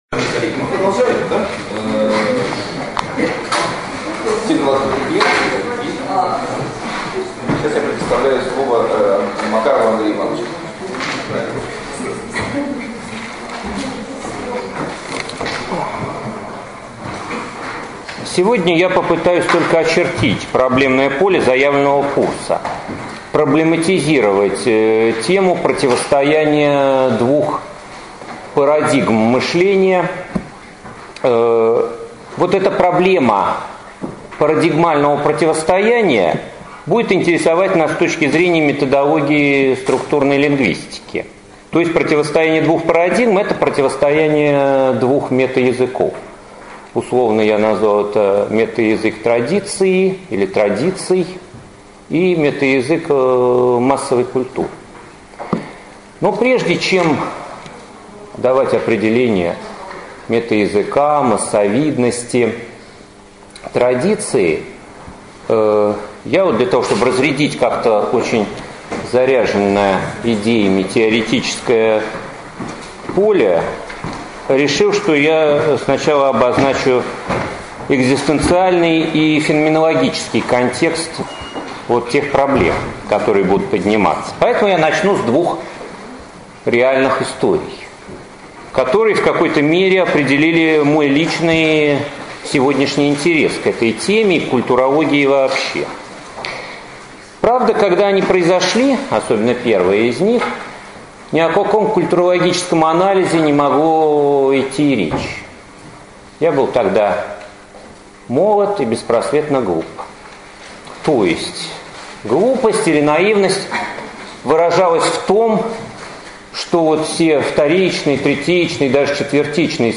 Аудиокнига Метаязык традиции и метаязык массовой культуры | Библиотека аудиокниг